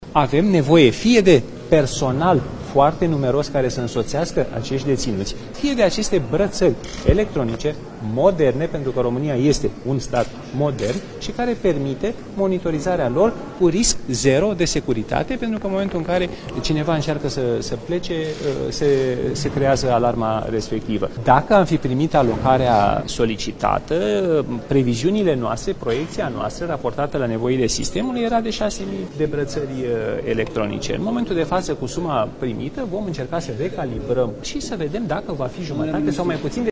Ministrul Justiției, Radu Marinescu: Dacă am fi primit alocarea solicitată, am fi putut cumpăra 6.000 de brățări electronice pentru deținuți